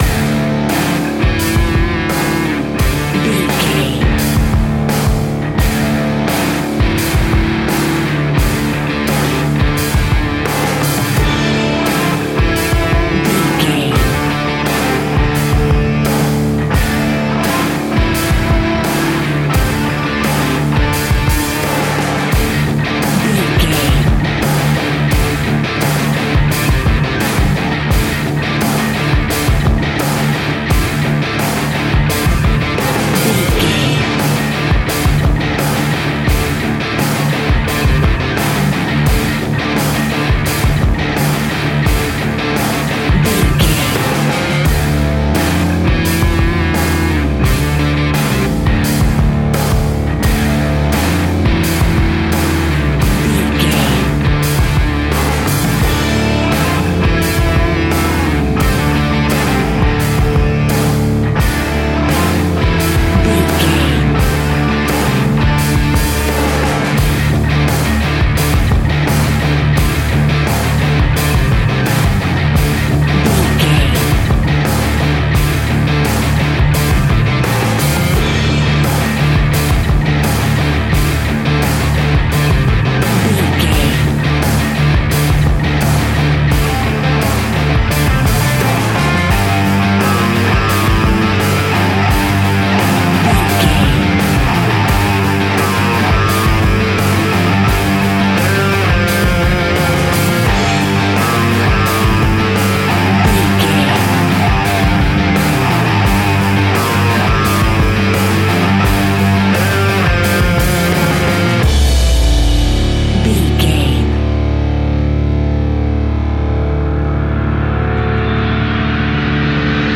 Ionian/Major
D♭
hard rock
heavy rock
distortion
instrumentals